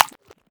دانلود افکت صوتی صدای کارتونی گل و لای